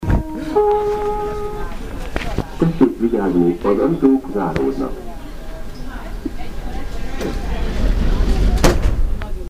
Departure Procedure and Sounds
"[Dong-ding] Tessek vigyazni, az ajtok zarodnak" ("Please be careful, the doors are closing"), pre-recorded male voice (